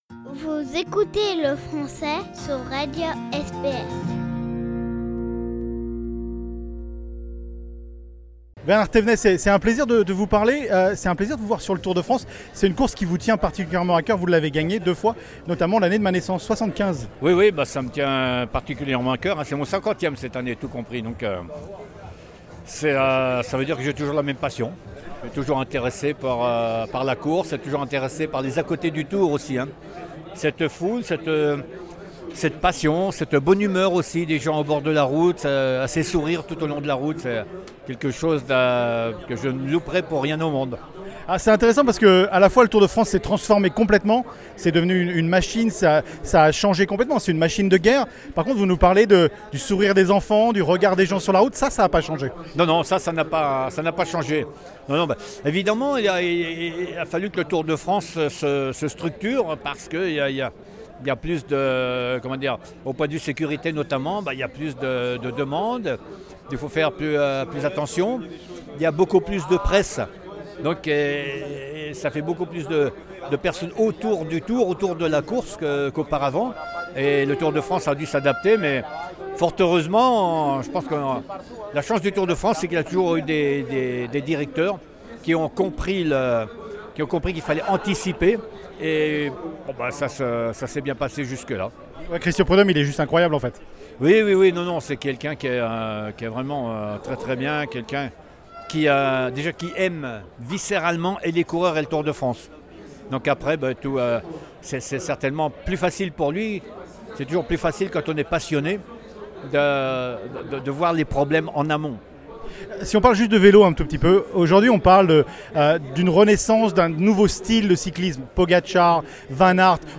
Sur les routes du Tour de France, nous avons rencontre Bernard Thévenet, vainqueur du Tour de France 1975, il fait maintenant partie de l'organisation.